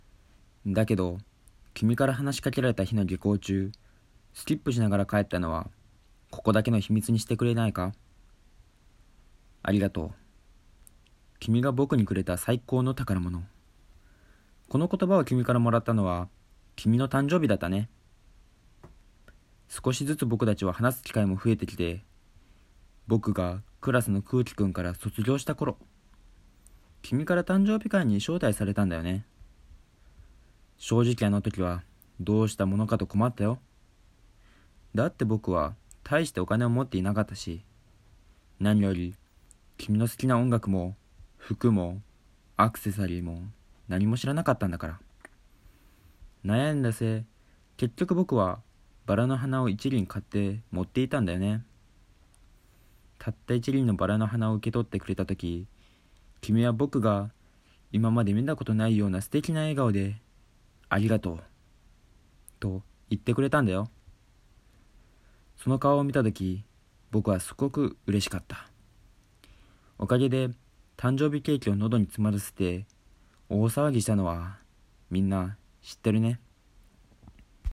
lisponキャスト審査朗読続き1